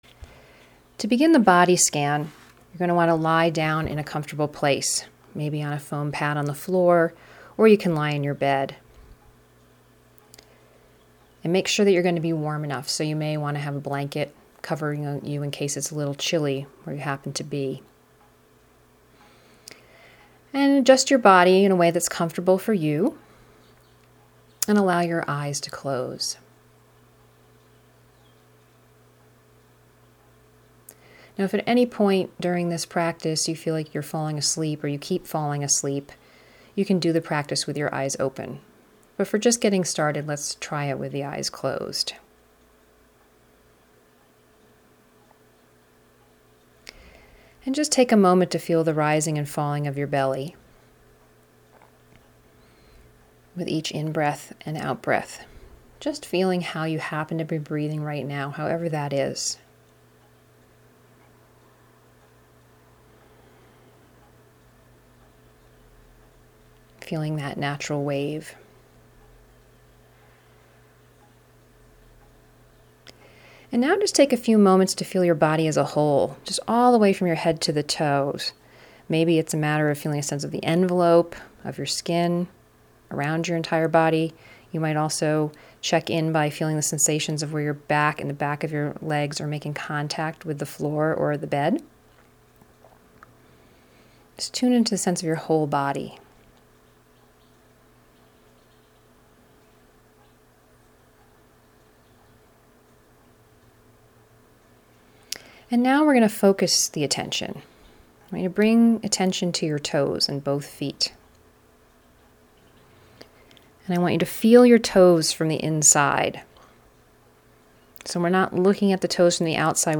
Guided-Meditation Audio Recordings
Short_Body_Scan_Meditation.mp3